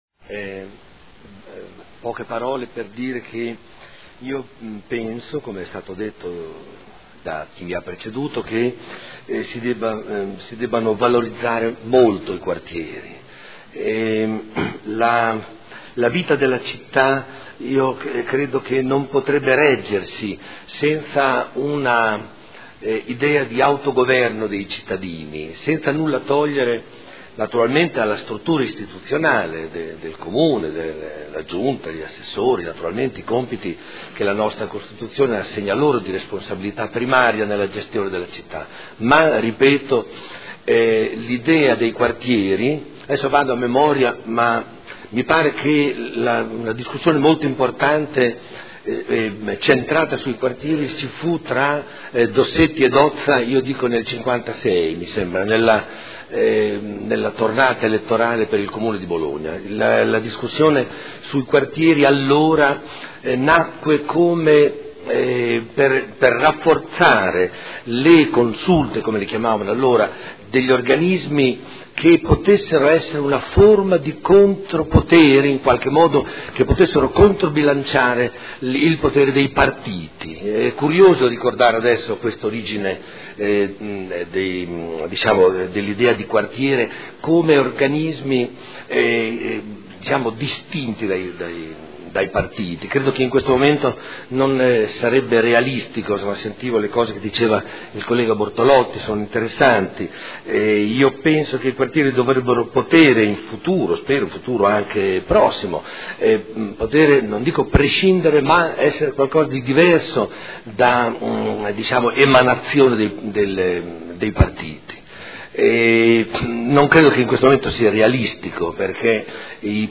Seduta del 16 ottobre. Proposta di deliberazione: Individuazione e nomina dei componenti dei Consigli di Quartiere (Conferenza Capigruppo del 16 ottobre 2014). Dibattito